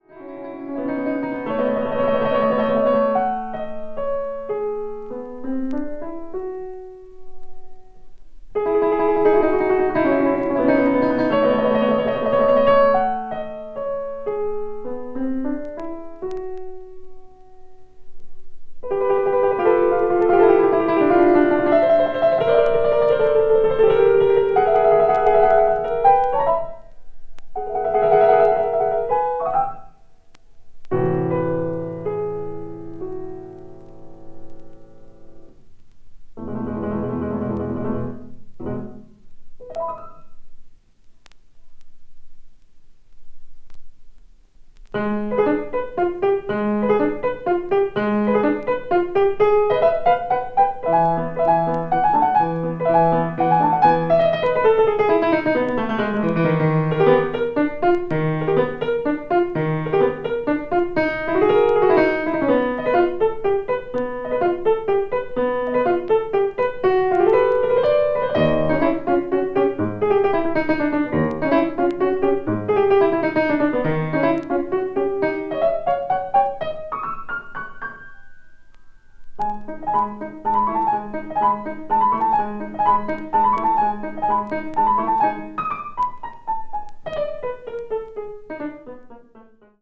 media : EX-/EX-(some light noises.)